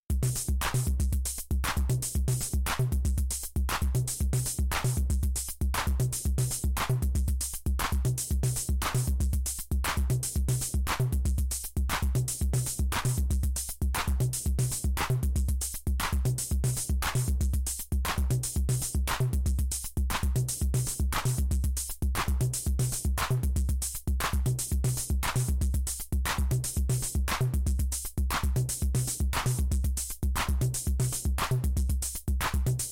破坏扭曲的节拍01
描述：一个简单的、缓慢的鼓点，来自一台旧的鼓机，通过一个手工制作的失真/过滤装置进行处理，
Tag: 过滤器 圈套 速度慢 808 失真